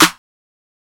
MZ Snareclap [Metro #2].wav